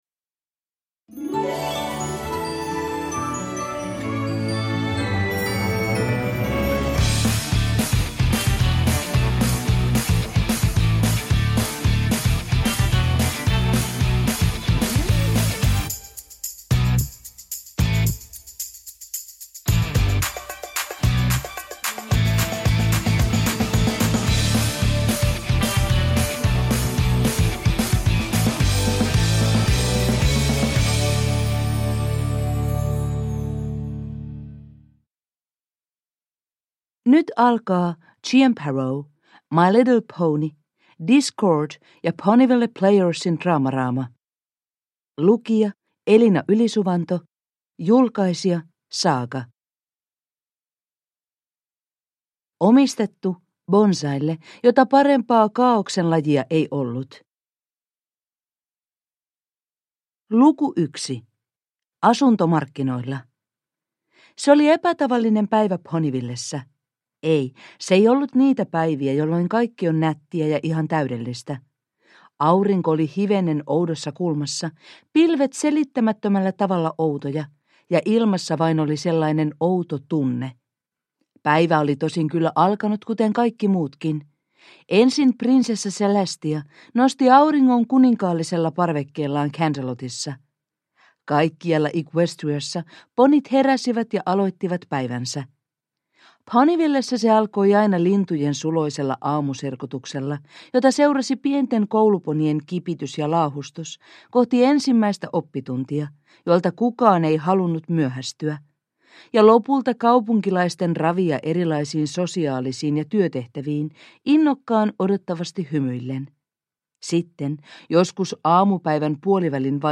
My Little Pony - Discord ja Ponyville Playersin Dramarama – Ljudbok